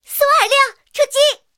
SU-26出击语音.OGG